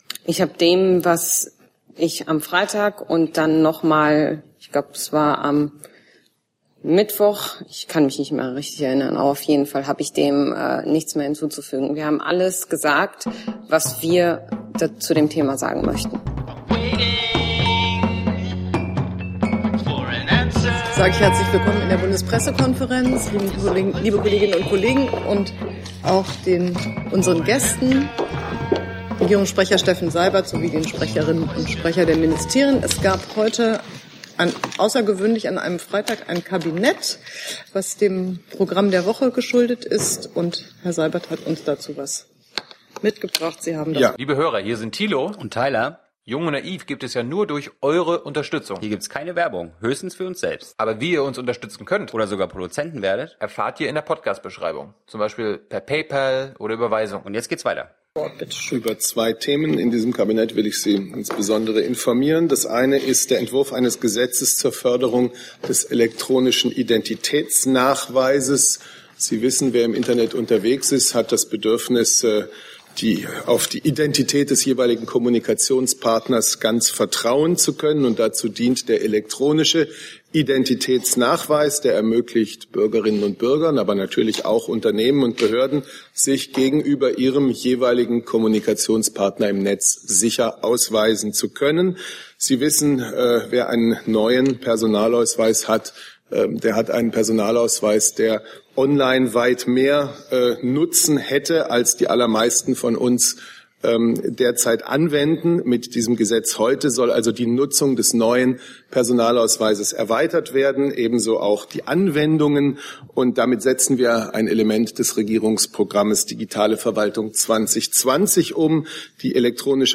RegPK - 09.12.2016 - Regierungspressekonferenz ~ Neues aus der Bundespressekonferenz Podcast